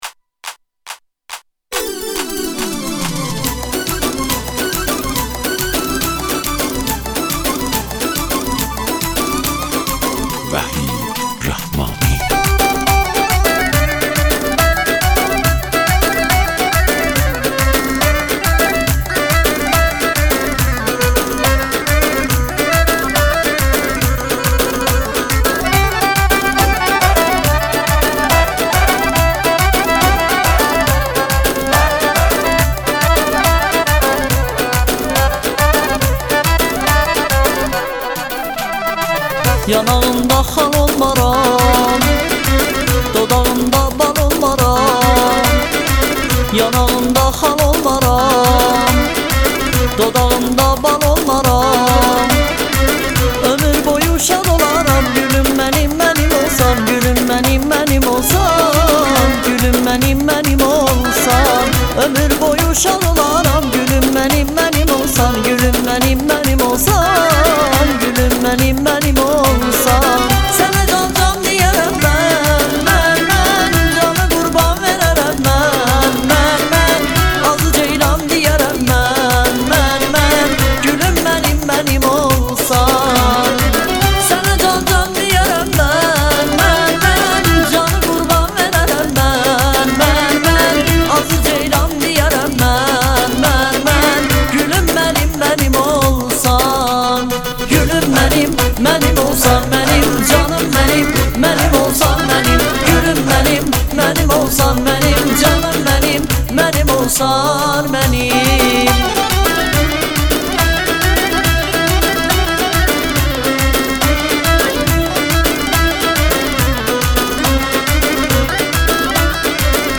اهنگ ترکی